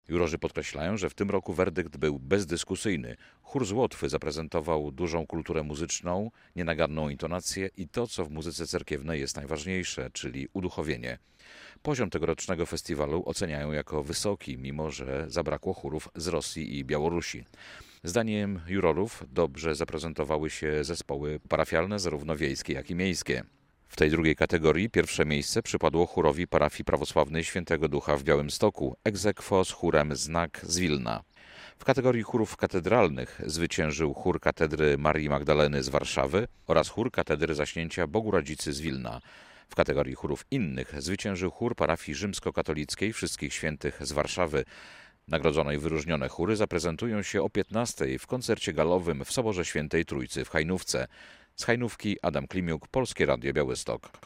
Laureaci 44. Międzynarodowego Festiwalu Hajnowskie Dni Muzyki Cerkiewnej - relacja